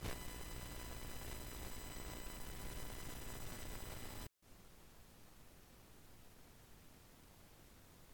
Hissing noise in recording
I tried recording and listening to it, and there's always an high frequency hissing noise, I can eliminate it sometimes, but I don't want to screw up the rest of the audio. I'm using an akg p170 to behringer ps400, using fender cables.
The recording is using the akg p170 and a cheap mic, the cheap broken mic doesn't appear to have any noise, so I don't think it's the soundcard problem, probably the adapters?